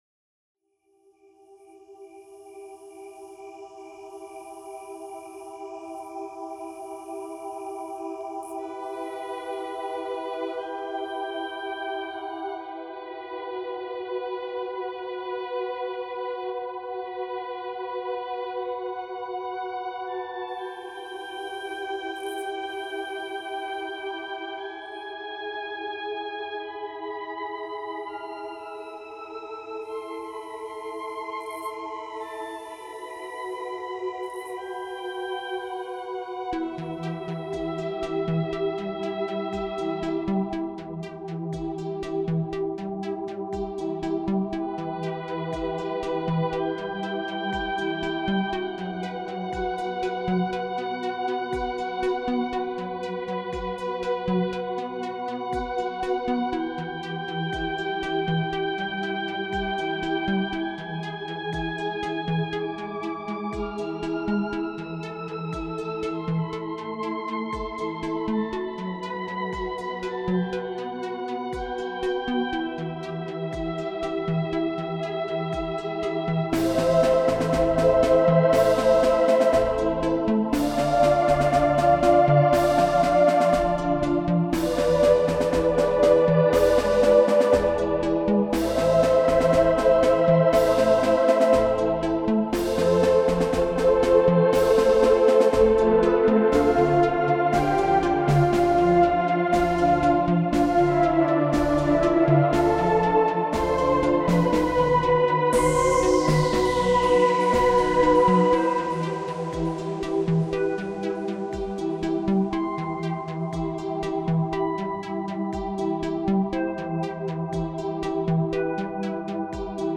Spacesynth Trance Techno Relax
Meditative Newage Space